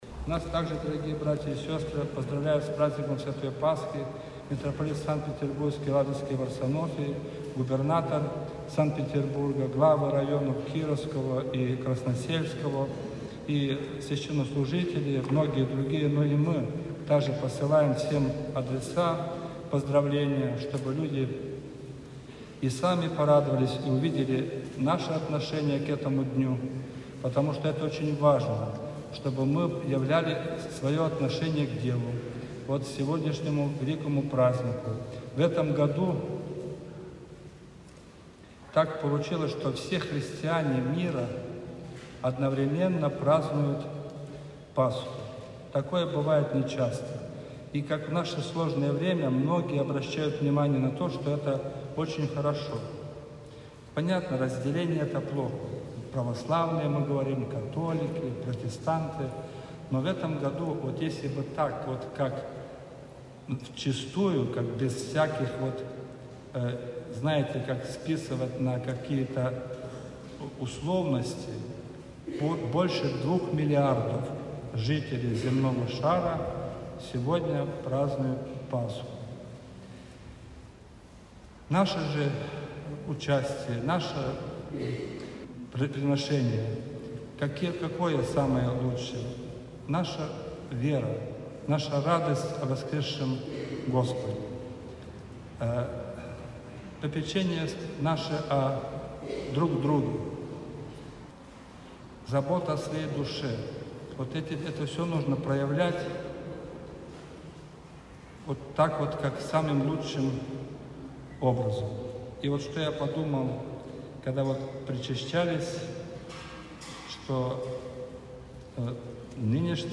Пасха.-Ночная-служба.mp3